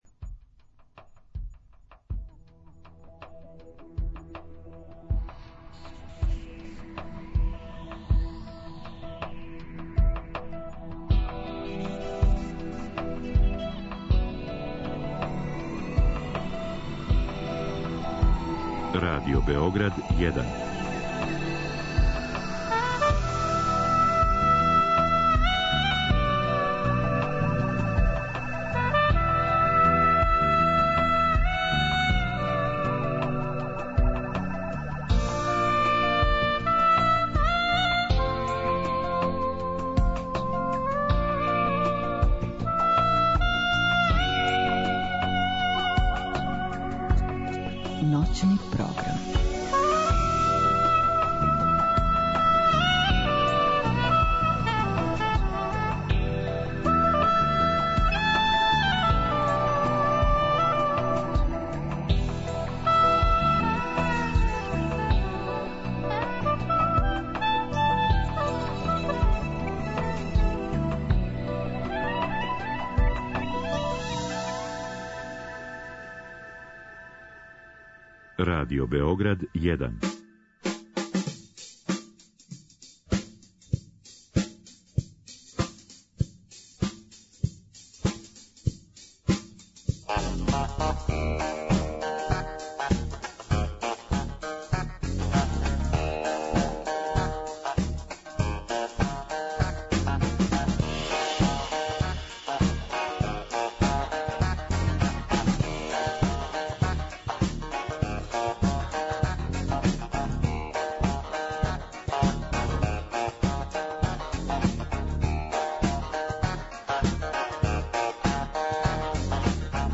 Bricks and Cans - свирају обраде rockabilly i rock 'n' roll хитова претежно с краја 50-тих и почетка 60-тих година, са нама у студију
Телефоном укључујемо Алена Исламовића.